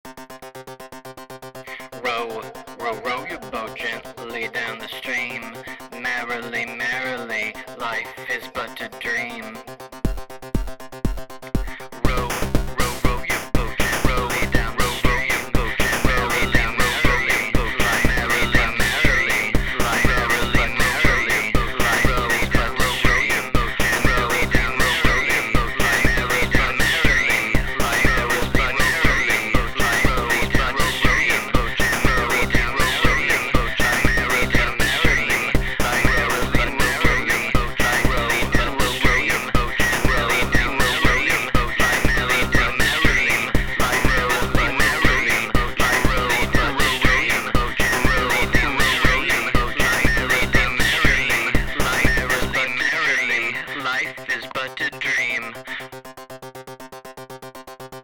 A TECHNO REMIX, because I figure what song isn't made better by a techno remix?
I would dance to that song for sure.
That song is so catchy!